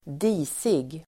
Uttal: [²d'i:sig]